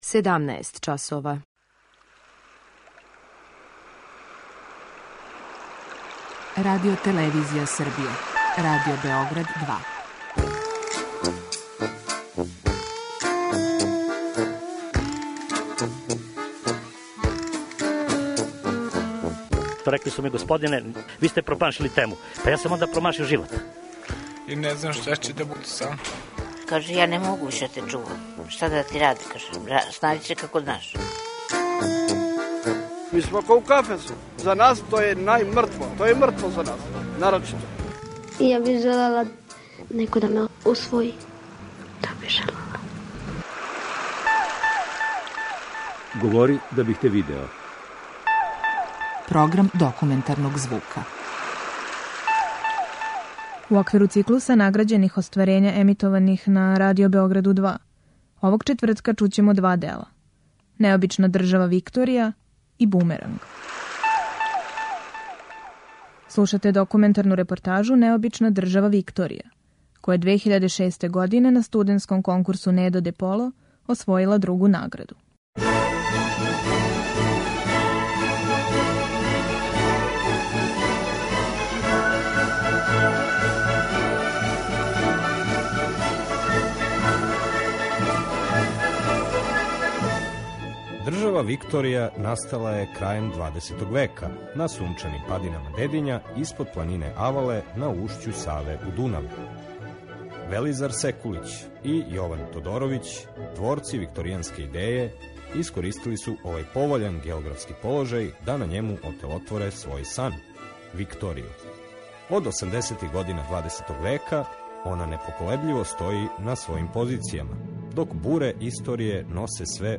Документарни програм - циклус награђених репортажа